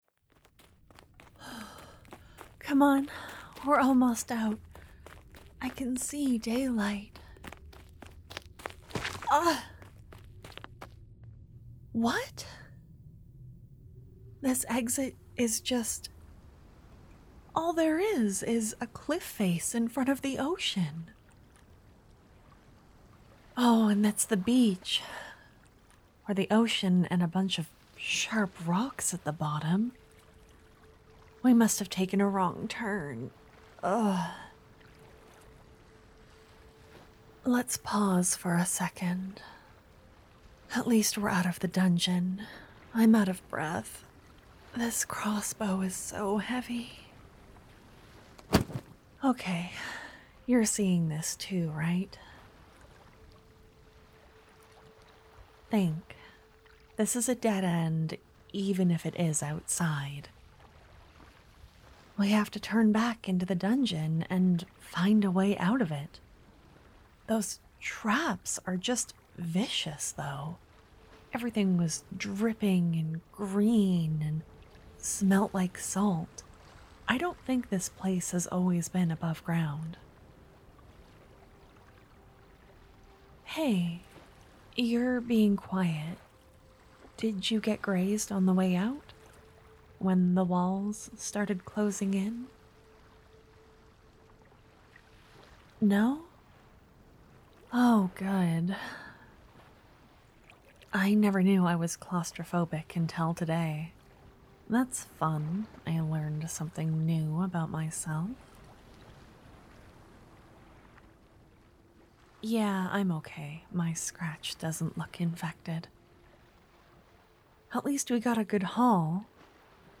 It's very sleepy (in the third act) with lots of ear to ear dialogue and ocean sounds.